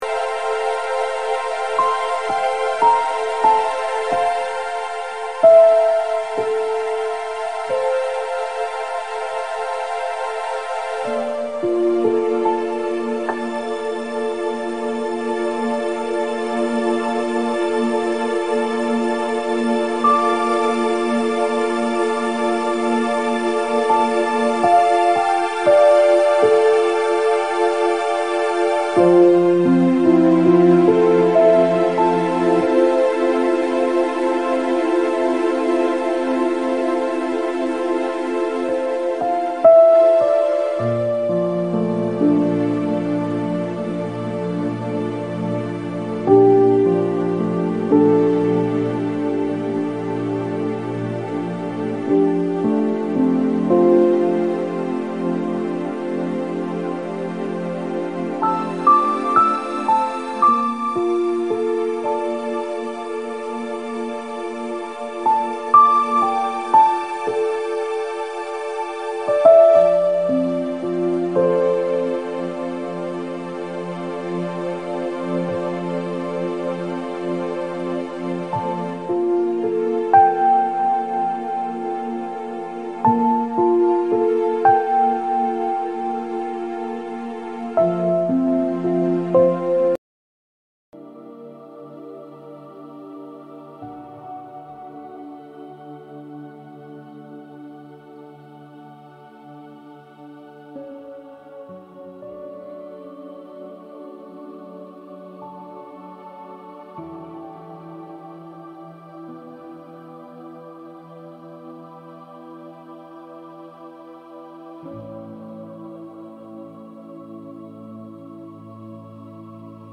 敬拜